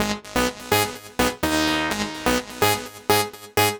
Dubnophonic Ab 126.wav